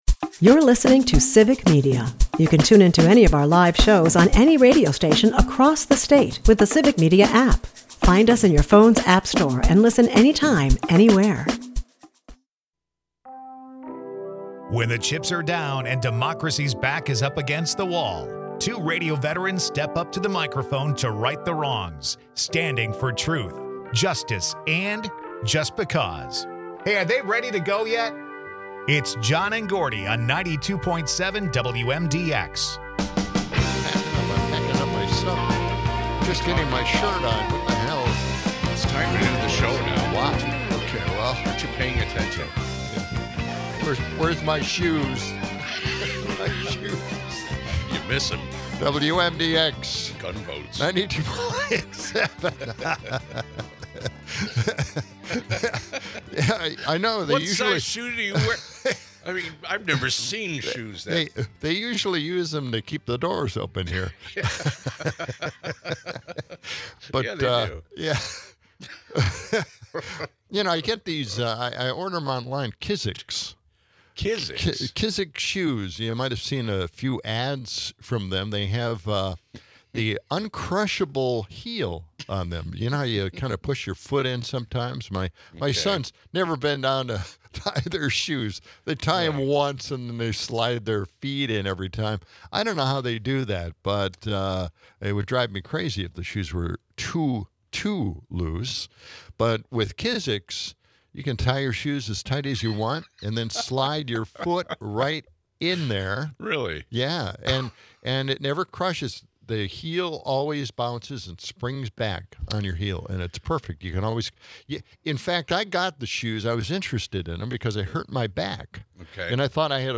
From a deep dive into the comfort of Kiziks shoes and their miraculous back-healing powers to the nostalgia of Beach Boys tunes, they keep it light yet lively. As clouds loom over Madison, they discuss the WIBA reunion and Madison Night Market happening today.